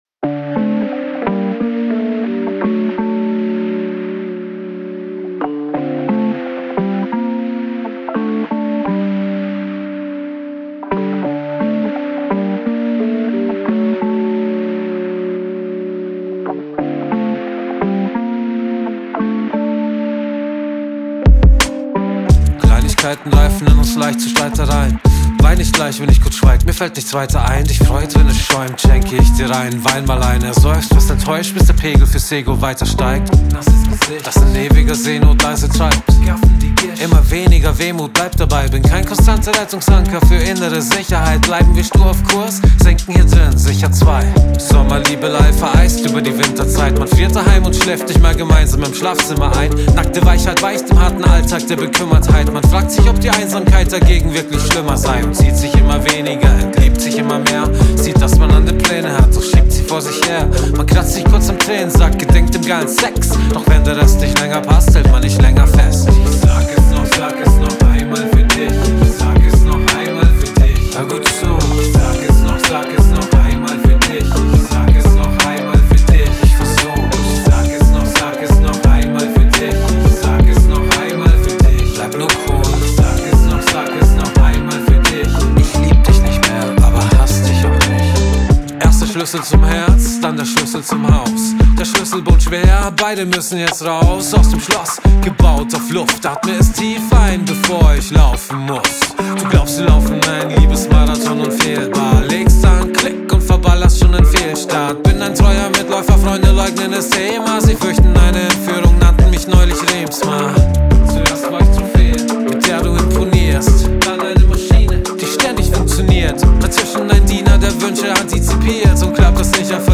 Wenn im Intro die Kick und Snare einsetzen, kommt in Mix 2 jetzt mehr Wumms, das gefällt mir.
Raum auf Main Vocs sehr toll Du hast die Bass-Line dominant gemacht